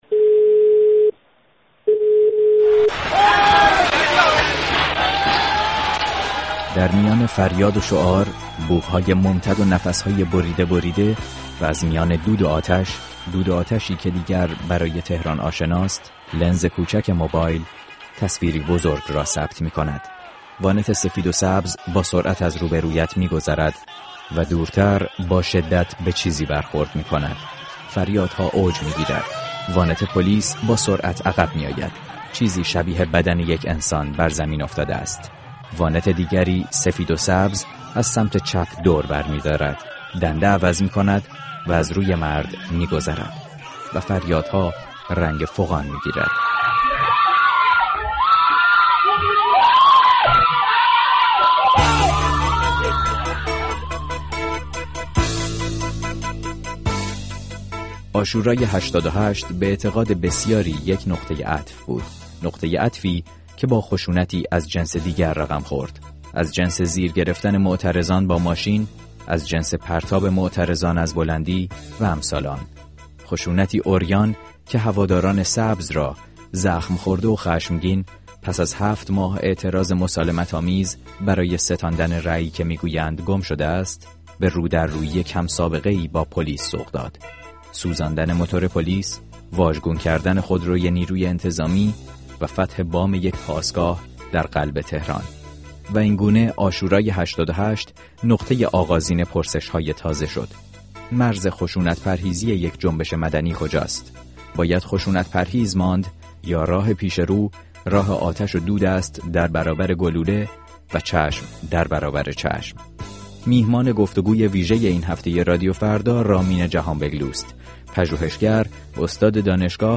گفت‌وگوی ویژه با رامین جهانبگلو: مرز خشونت پرهیزی یک جنبش مدنی کجاست؟